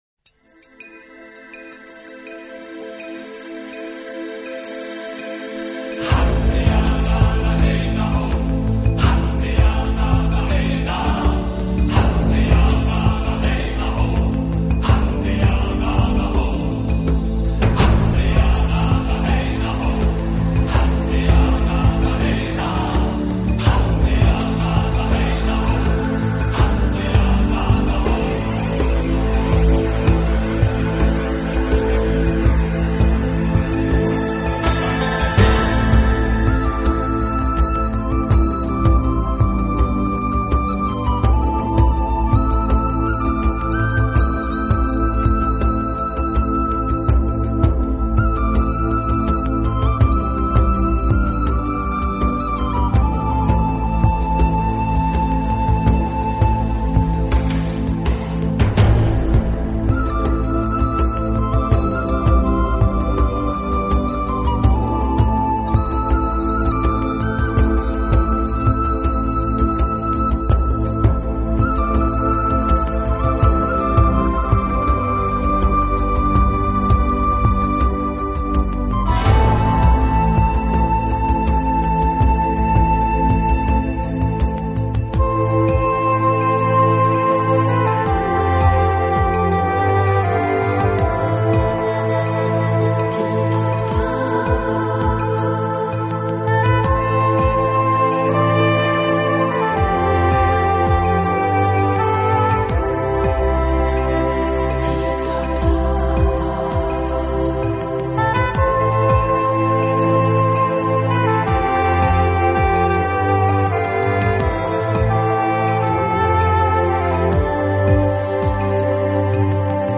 这种音乐是私密的，轻柔的，充满庄严感并总
混合了电子和天空的声音，并将民族元素编入了交响乐结构中以创造其明显而独